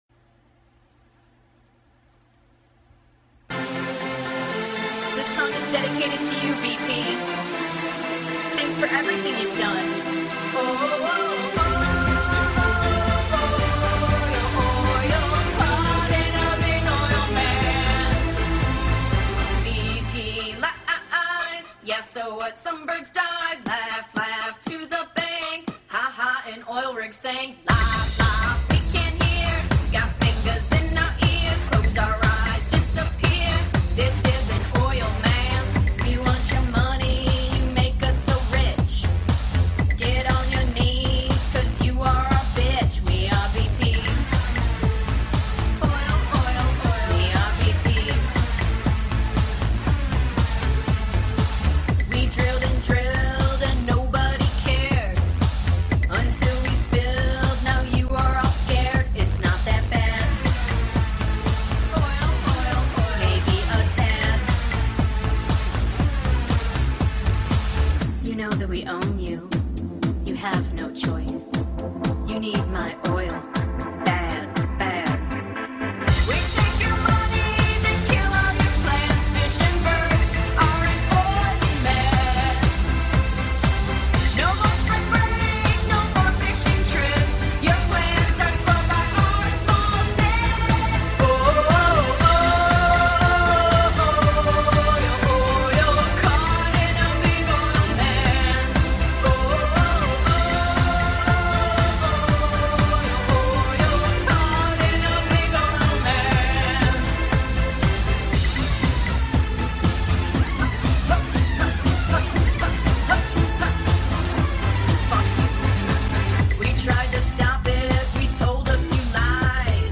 song parody